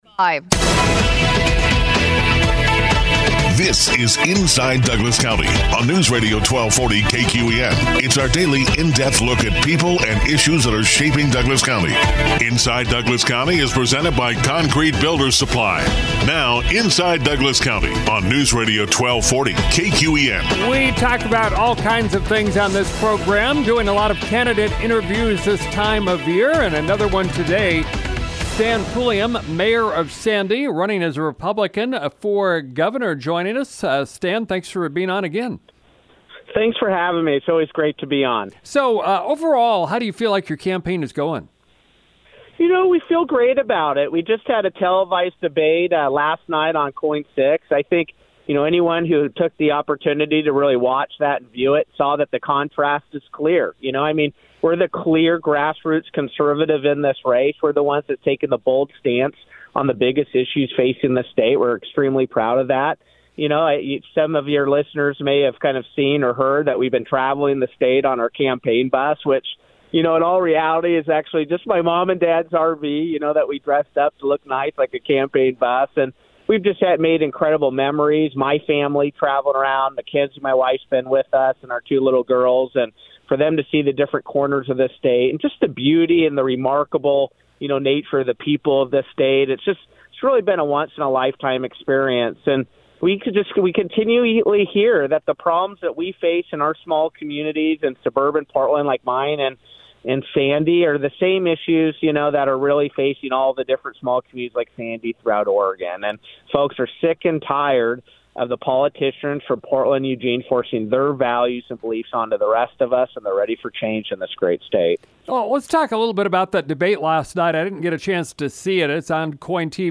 Republican candidate for Governor Stan Pulliam talks about his campaign and shares his views on a number of issues: Click here to download for later listening; IDC 4 29 22